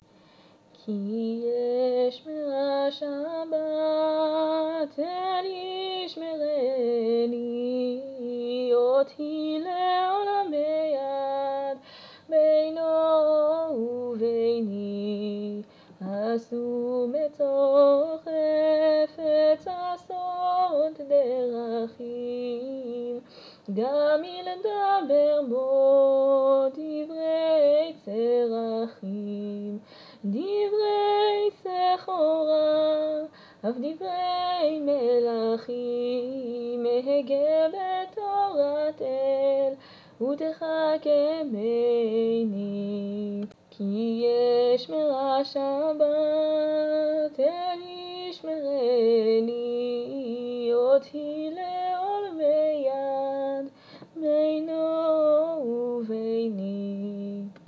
כי אשמרה שבת - שירת נשים!
זהו השיר "כי אשמרה שבת" בביצועי ללא ליווי כלי נגינה (בית ראשון בלבד).